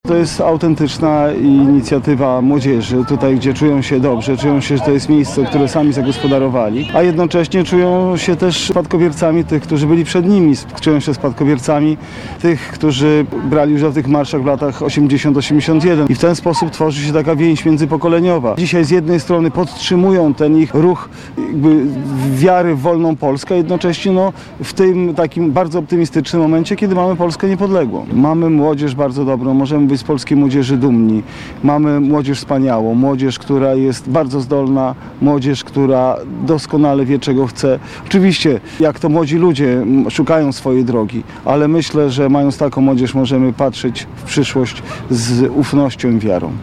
– Jesteśmy tutaj, ponieważ chcemy zamanifestować nasz patriotyzm – mówili uczestnicy marszu.